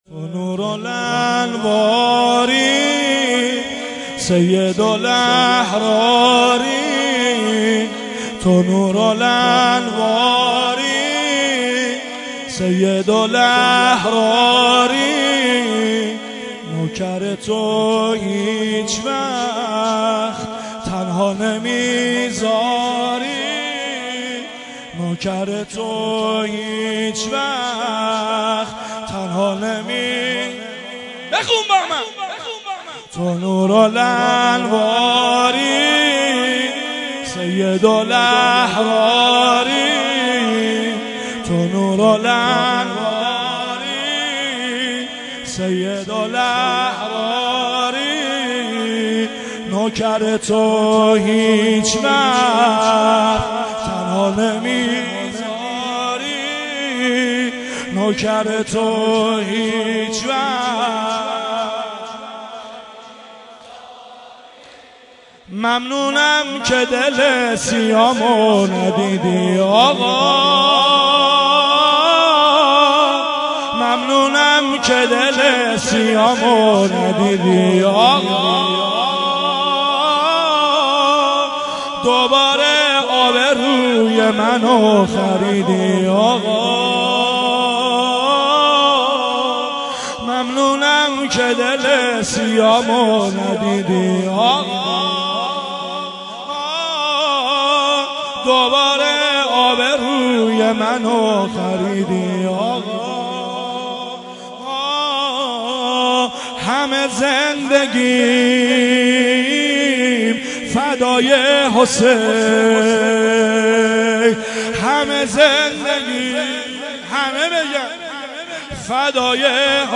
مراسم سالگرد شهید مدافع حرم